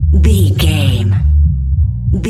Electronic loops, drums loops, synth loops.,
Epic / Action
Fast paced
In-crescendo
Ionian/Major
Fast
epic
industrial
driving
energetic
hypnotic